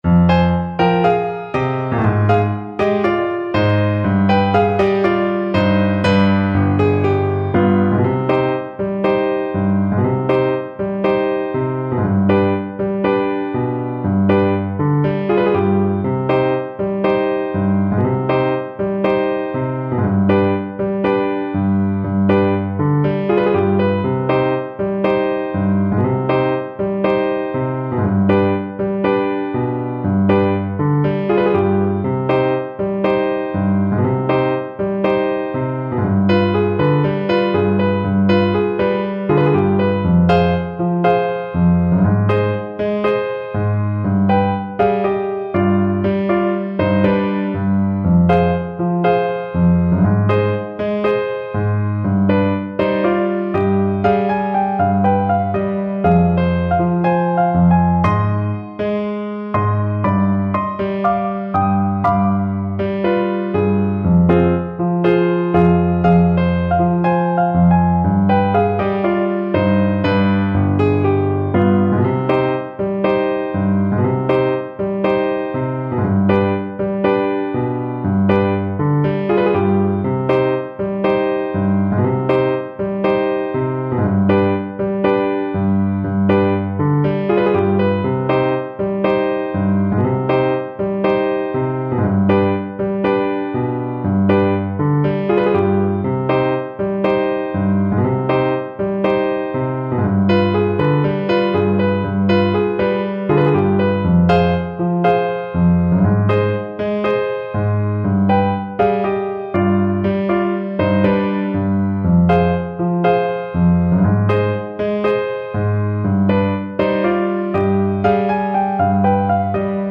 French Horn
Traditional Music of unknown author.
2/2 (View more 2/2 Music)
F4-G5
Slow two in a bar =c.60